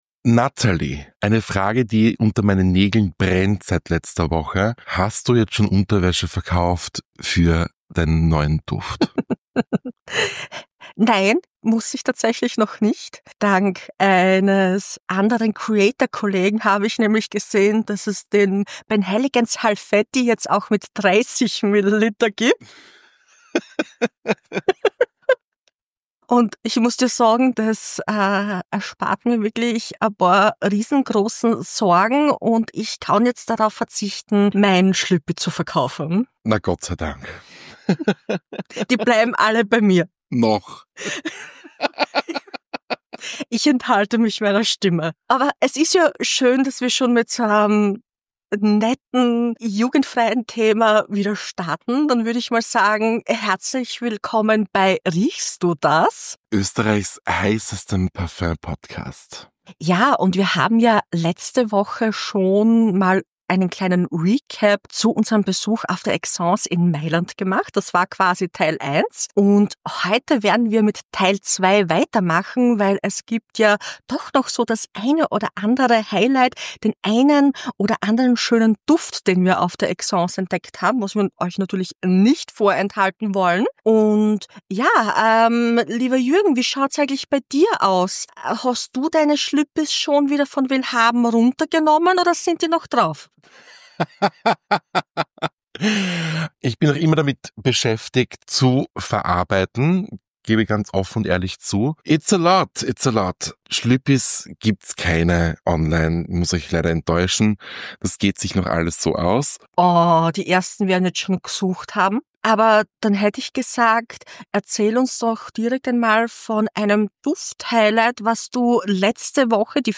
Wofür genau, welche Parfum Neuheiten die beiden gar nicht erwarten können und wieso es schon wieder um Schlüppis geht, hörst du in der neuen Folge - in frischer Sound-Qualität!